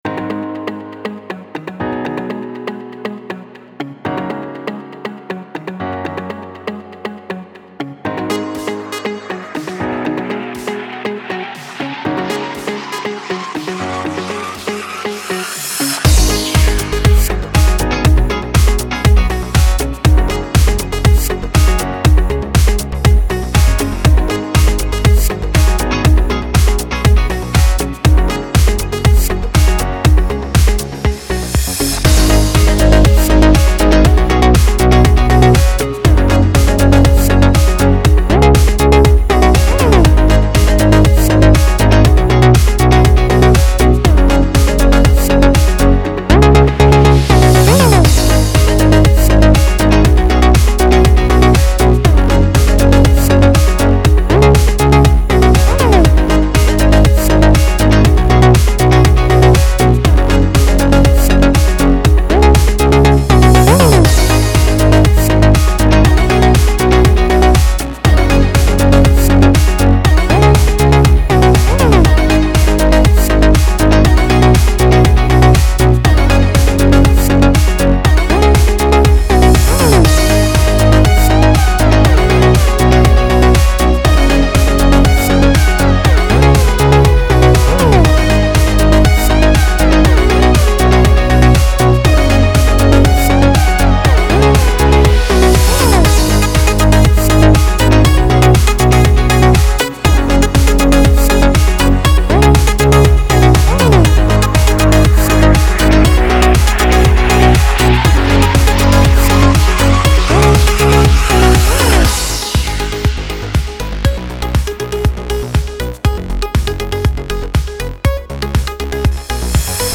موسیقی بی کلام الکترونیک
الکترونیک , پر‌انرژی